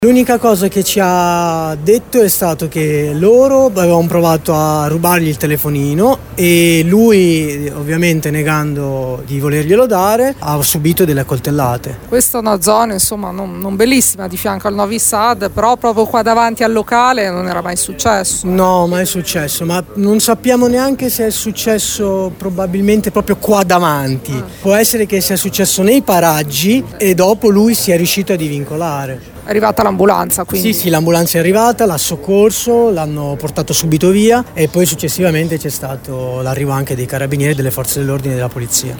Sentiamo una delle persone che lo ha soccorso: